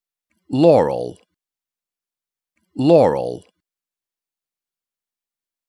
Is the voice saying “Laurel” or “Yanny”?
However, due to audio compression, frequency filtering, and playback device differences, many listeners perceived it as “Yanny.”
The Yanny/Laurel clip contains overlapping high and mid frequencies that correspond to different phonetic elements:
• High-frequency content → tends to be perceived as “Yanny
• Lower-frequency content → tends to be perceived as “Laurel